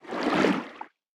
Sfx_creature_seamonkey_swim_fast_02.ogg